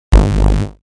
SE（ブー 不正解 失敗）
ブー。 不正解。